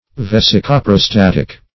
Search Result for " vesicoprostatic" : The Collaborative International Dictionary of English v.0.48: Vesicoprostatic \Ves`i*co*pro*stat"ic\, a. (Anat.)
vesicoprostatic.mp3